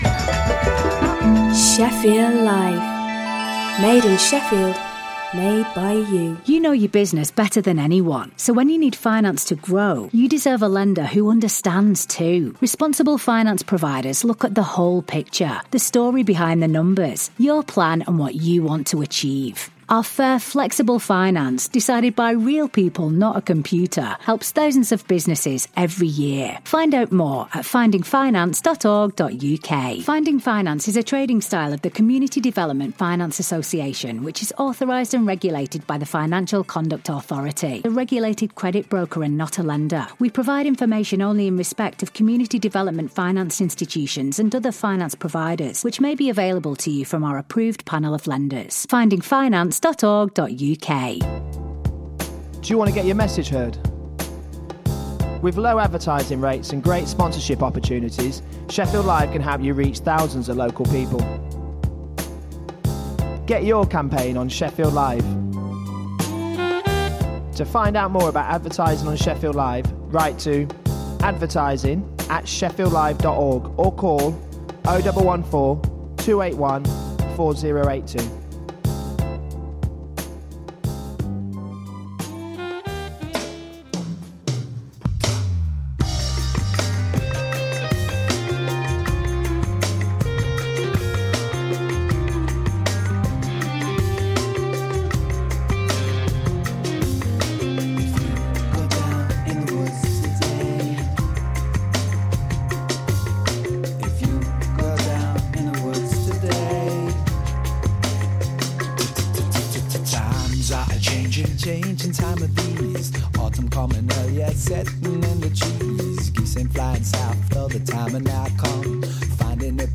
Adal Voice of Eritreans is a weekly radio magazine programme for the global Eritrean community.